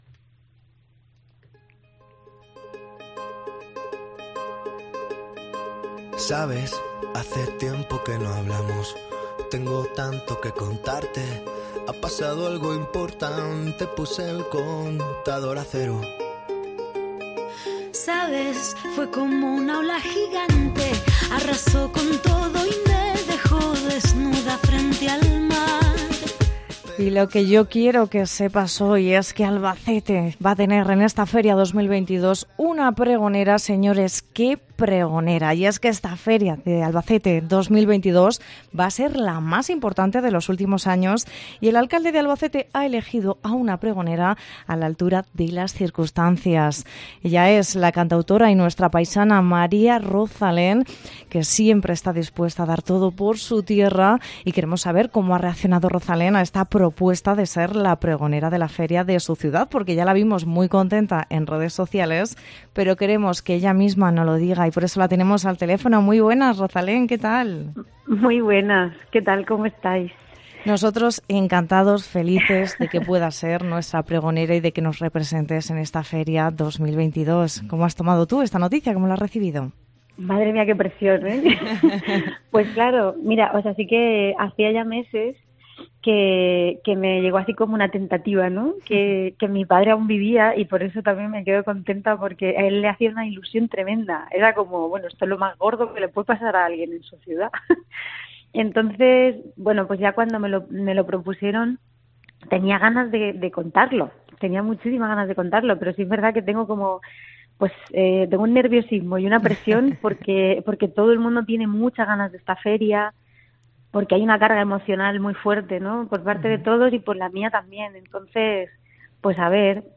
Entrevista con María Rozalén, pregonera de la Feria 2022
Escuchamos su risa contagiosa, cuando le preguntamos por el pregón del humorista Joaquín Reyes, que dejó un gran recuerdo de su intervención hace ya 13 años.